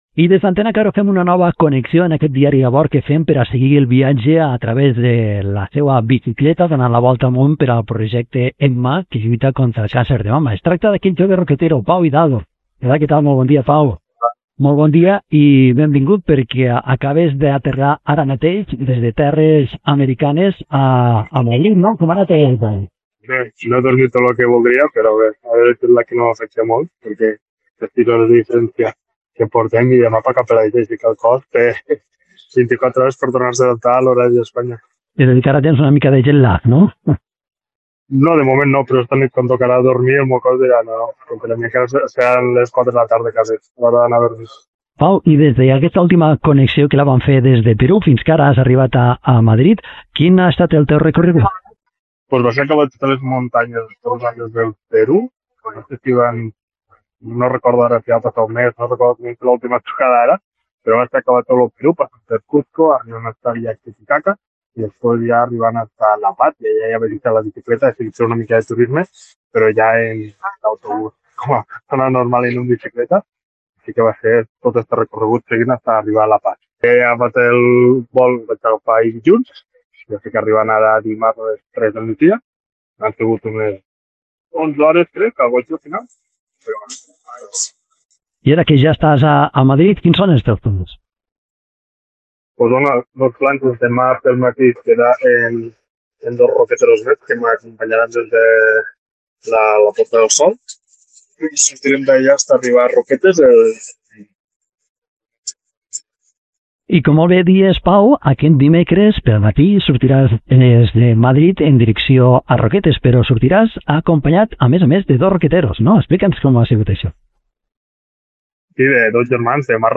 Entrevistem